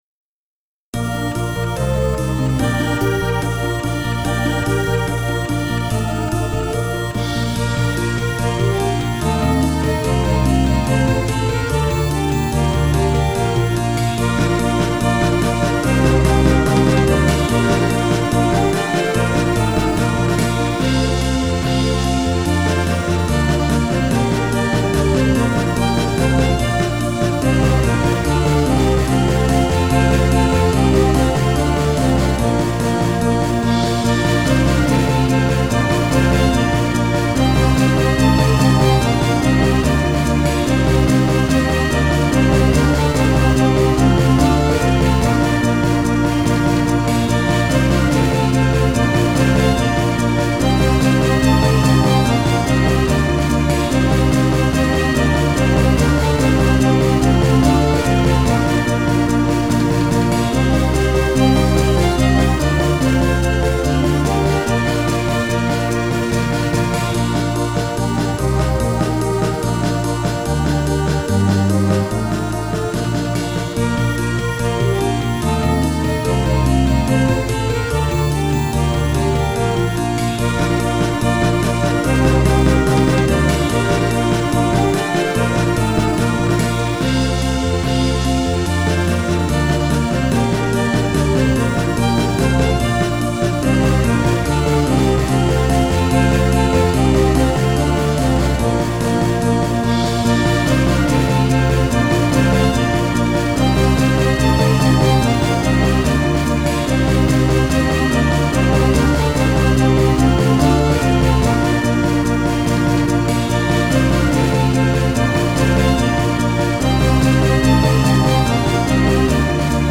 変ハ長調